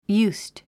発音
júːst　ユースト